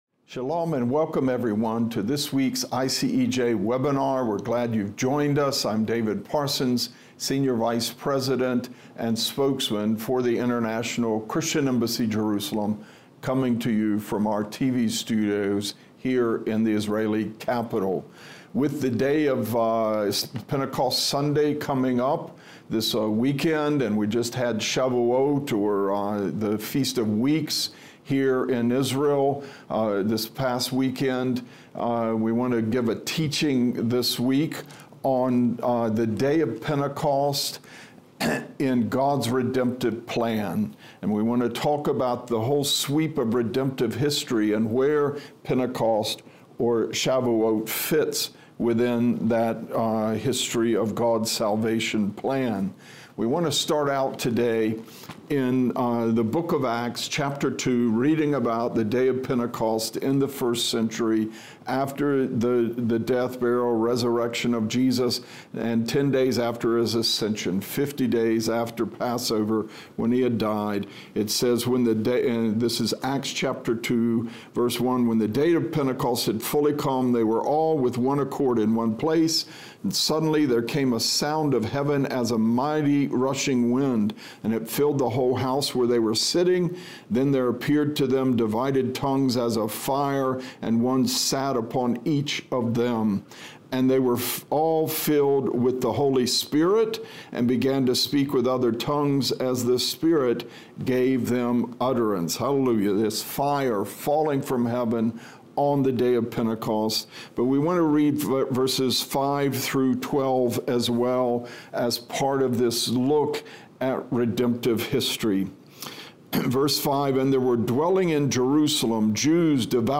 Listen to our Webinar this Thursday for an inspiring Biblical & Prophetic teaching: The Day of Pentecost in God’s Redemptive Plan.